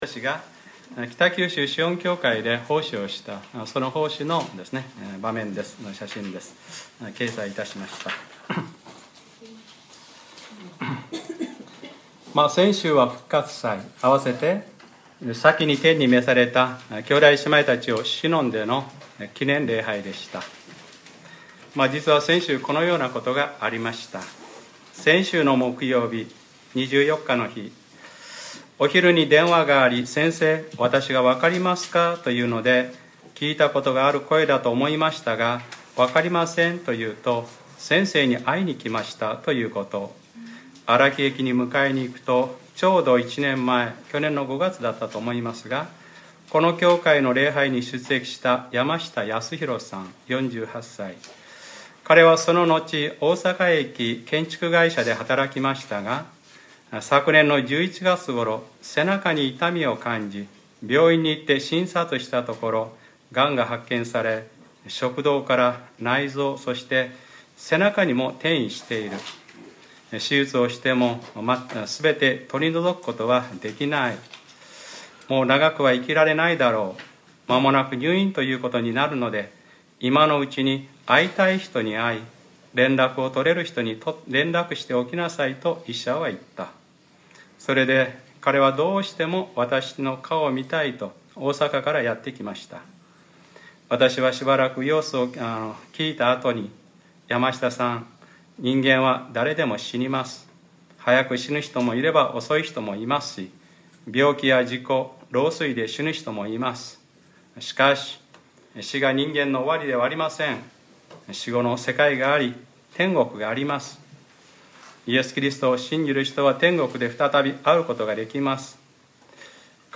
2014年４月27日（日）礼拝説教 『黙示録ｰ２８：小羊の婚姻の時が来て』